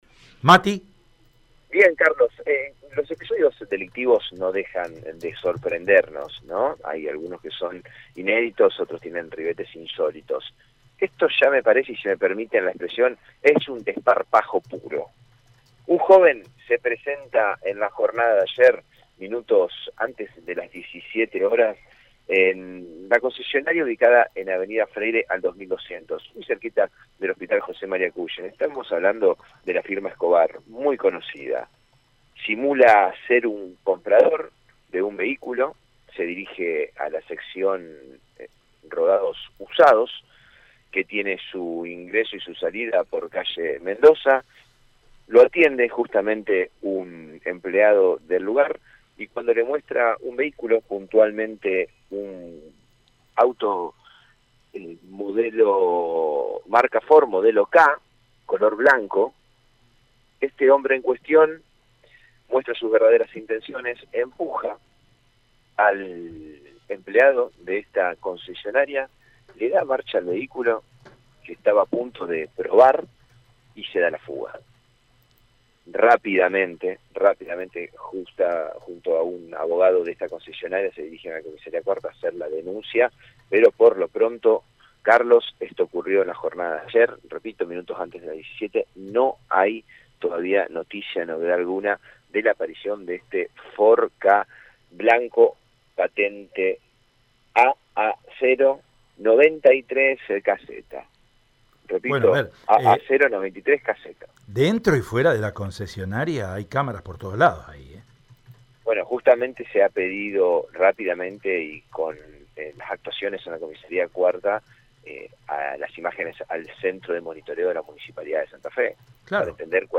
AUDIO DESTACADOPolicialesSanta Fe